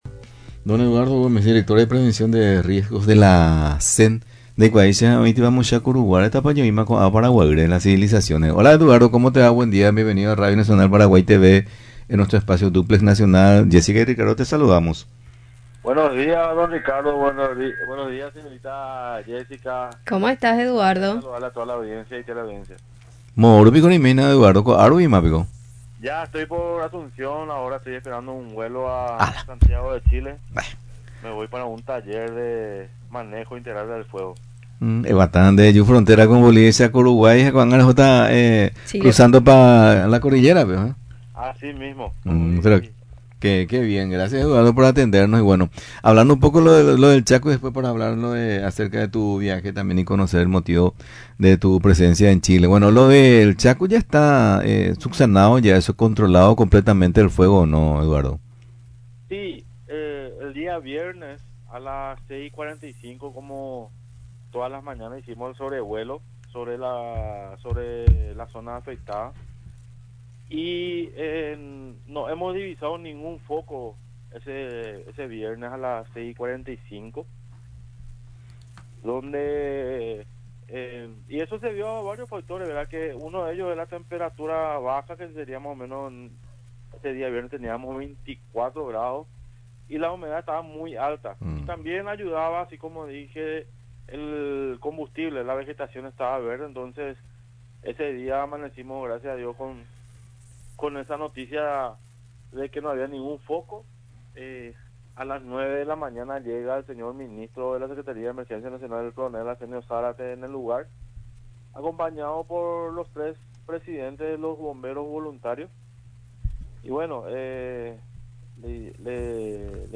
El director de prevención de riesgos de la Secretaría de Emergencia Nacional (SEN), Eduardo Gómez, fue entrevistado en Radio Nacional, sobre la situación de los incendios en el Bahía Negra, Alto Paraguay y su próximo viaje a Chile. En primer lugar, confirmó que el fuego en la zona del Chaco ya está completamente controlado.